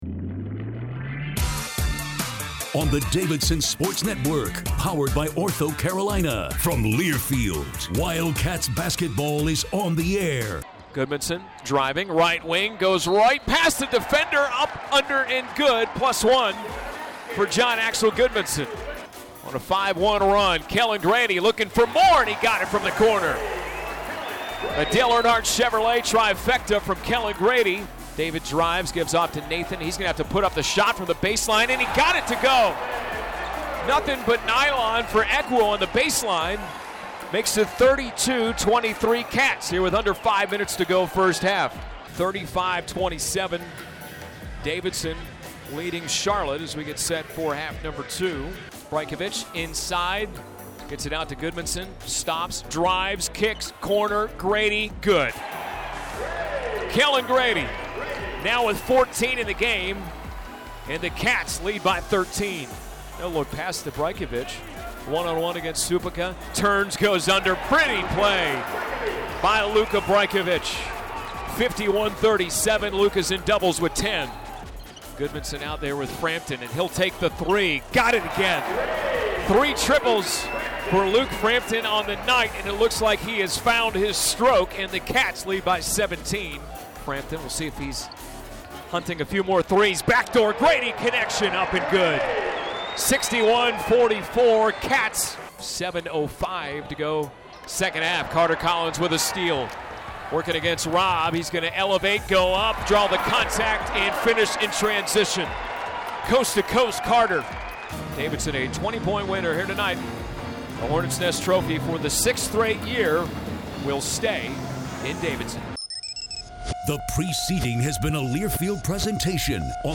Radio Highlights